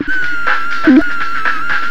FXBEAT06-L.wav